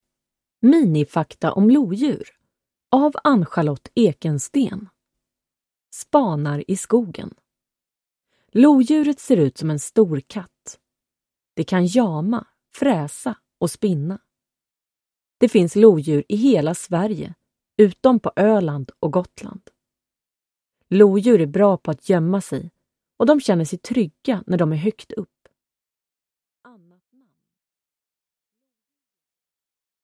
Minifakta om lodjur (ljudbok